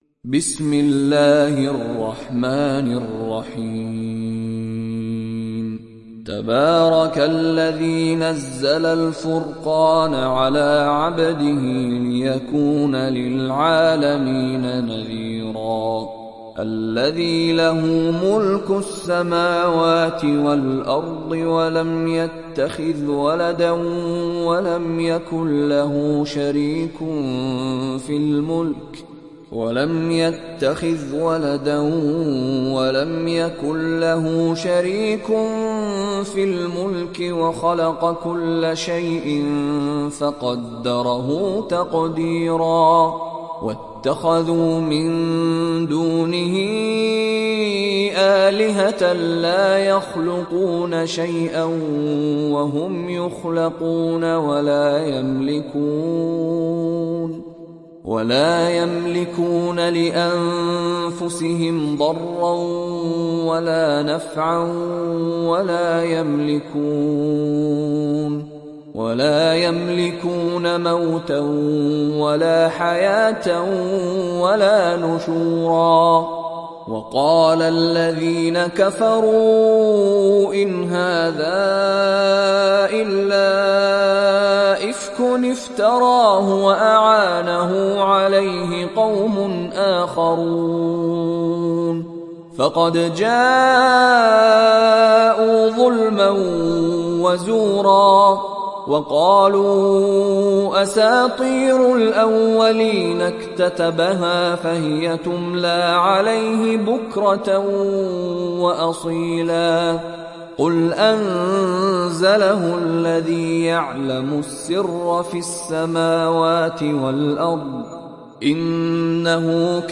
Surat Al Furqan mp3 Download Mishary Rashid Alafasy (Riwayat Hafs)